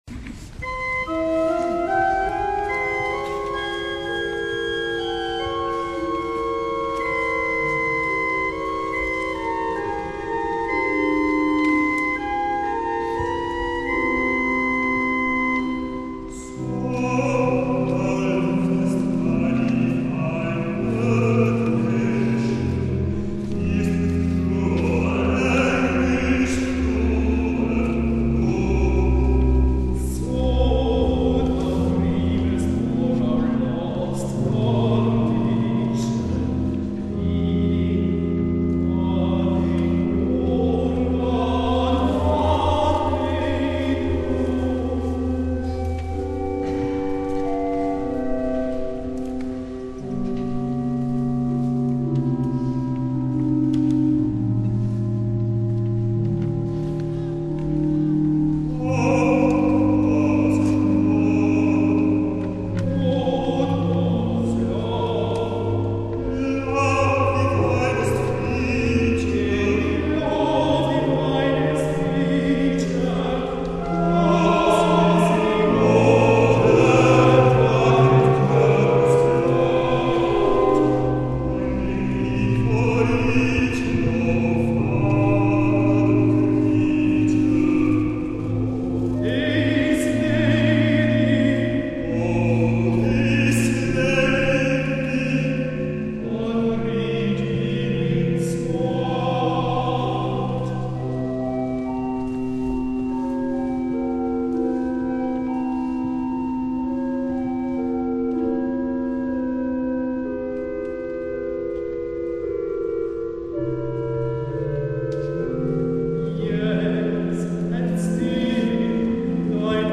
Duet: